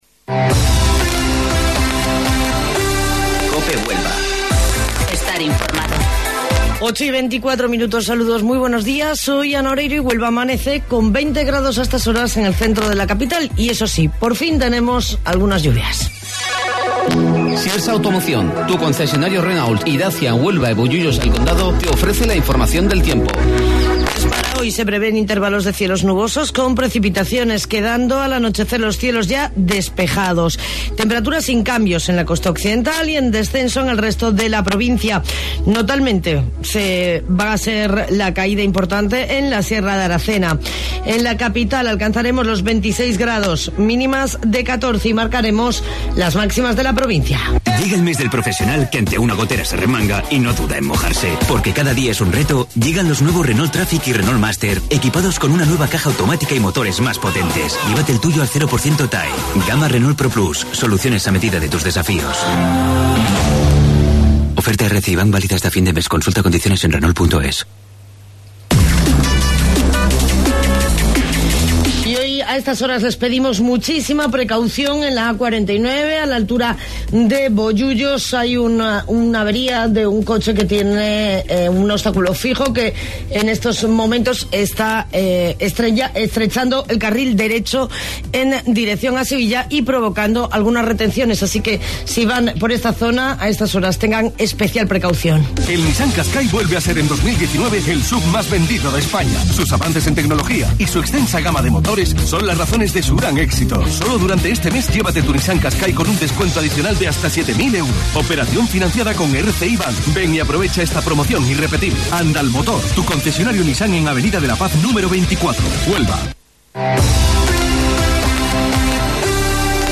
AUDIO: Informativo Local 08:25 del 14 de Octubre